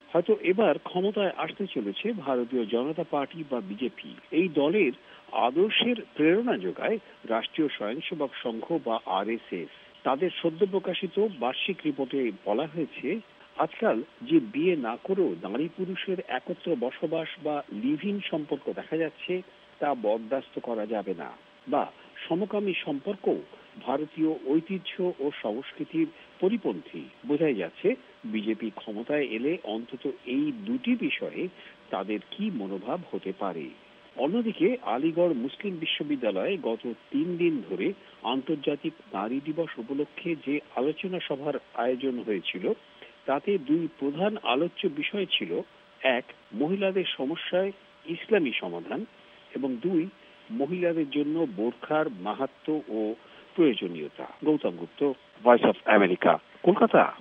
ভয়েস অফ এ্যামেরিকার কলকাতা সংবাদদাতাদের রিপোর্ট